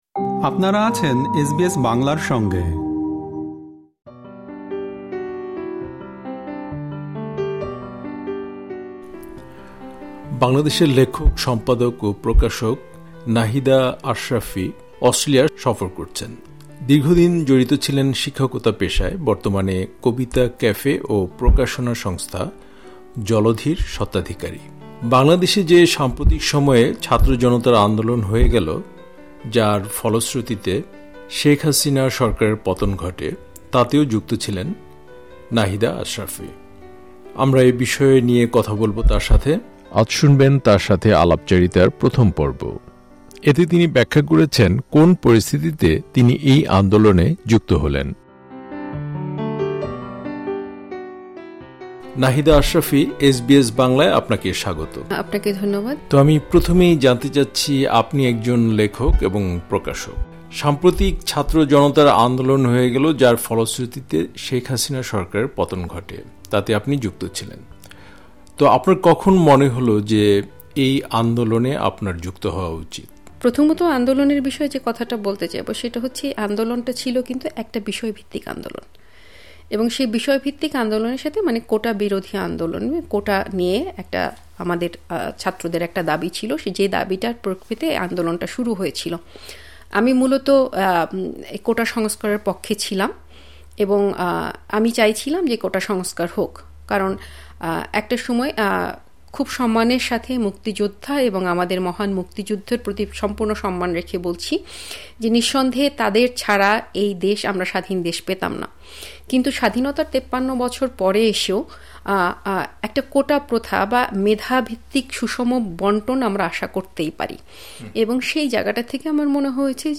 এখানে প্রকাশিত হলো তার সাথে আলাপচারিতার প্রথম পর্ব।